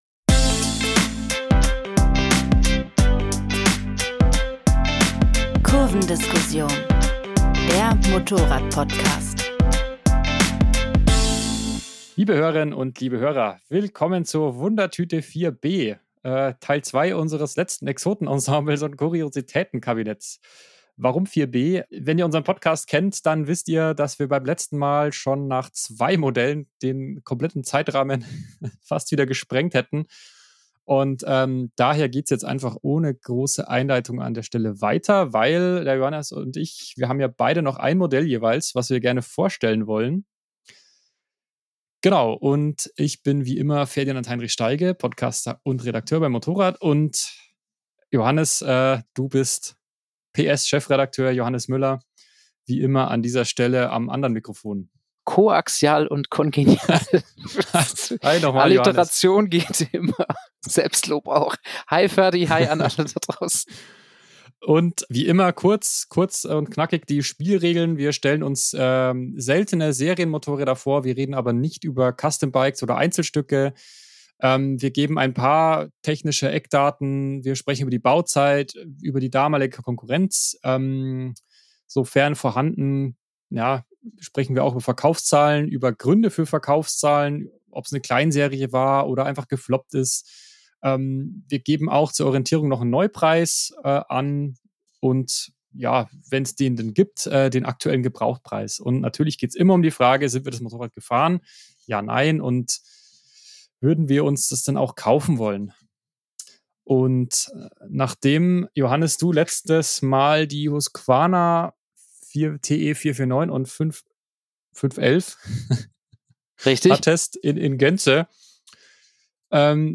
Kurvendiskussion, das sind Benzingespräche am MOTORRAD-Stammtisch, mit Redakteuren und Testern. Es geht um aktuelle Modelle, Trends, Schrauberphilosophie und alles, was uns sonst bewegt. Auch die ein oder andere Anekdote aus dem Redaktionsalltag, die es im Heft nicht zu lesen gibt.